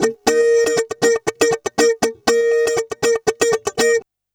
120FUNKY08.wav